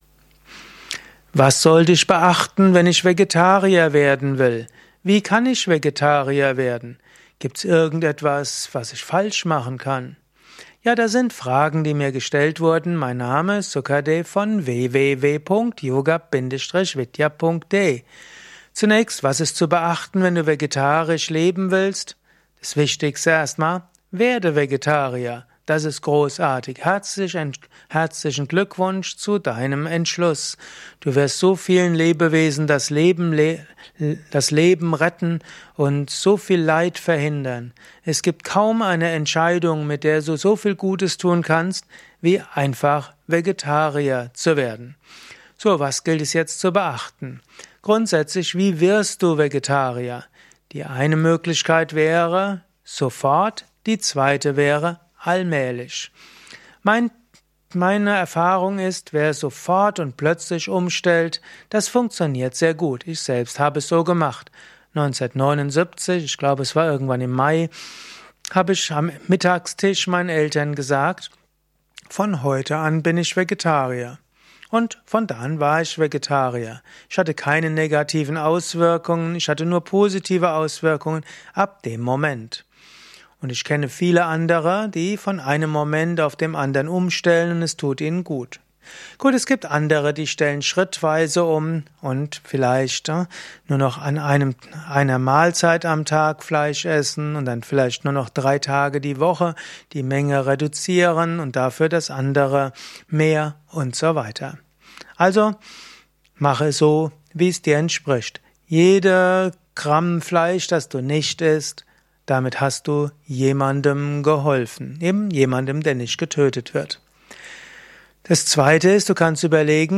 In diesem Vortrag